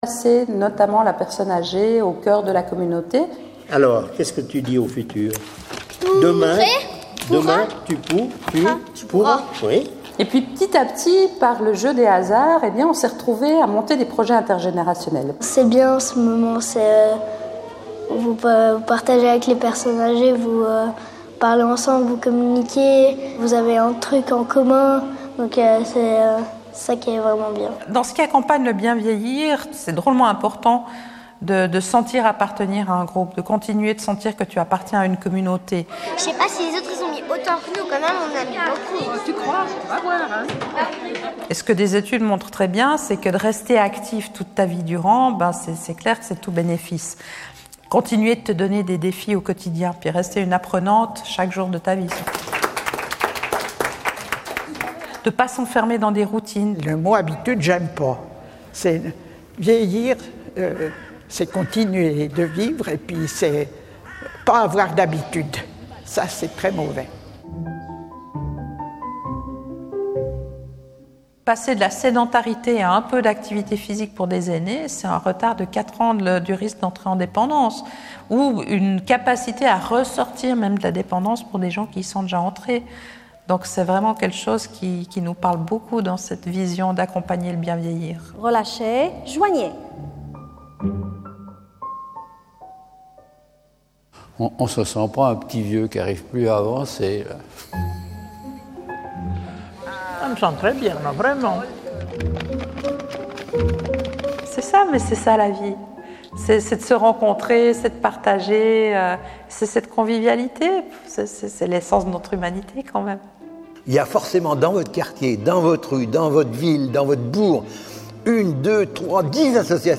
Vous pouvez réécouter cette conférence, qui débute par un extrait d'une vingtaine de minutes du film “De plus en plus en vie”.
La conférence est disponible en replay audio